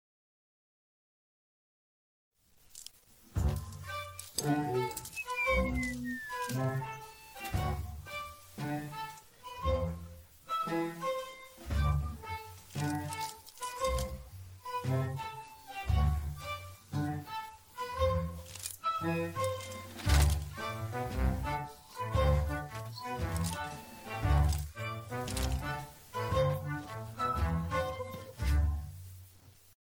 gameBGM.MP3